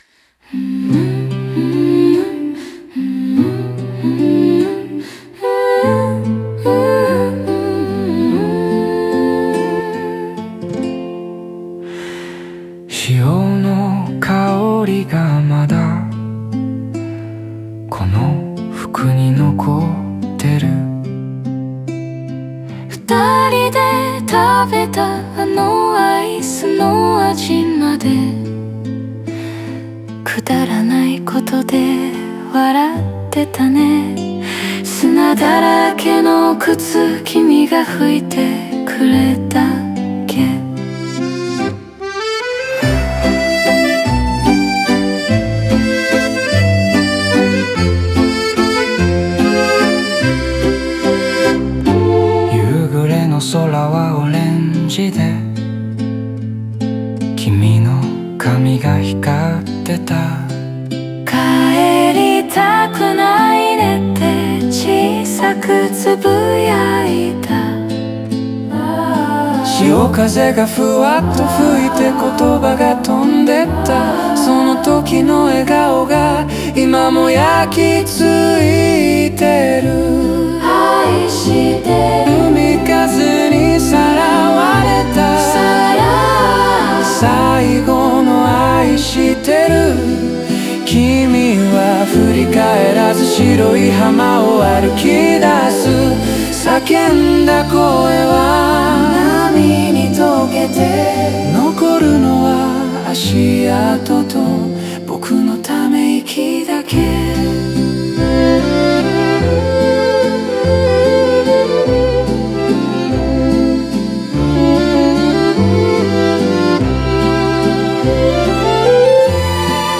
オリジナル曲♪
海辺の静けさと心のざわめきが、3拍子のやわらかなリズムに重なり、切なさと温かさが同居する余韻を生みます。
Music(音楽) Music(音楽) (1645)